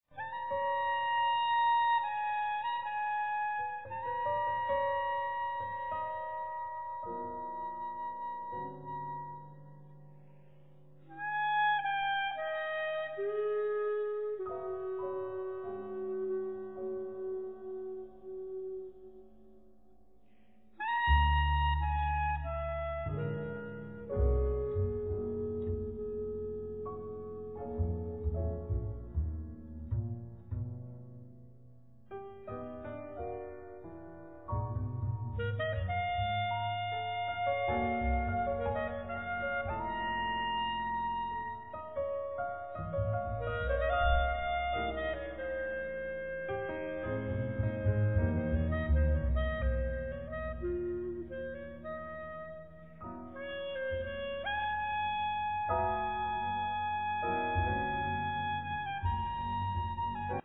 Clarinet
Bass
Sax
Trumpet
Drums, Percussions